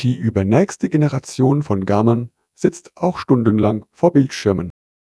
Added tts audio samples.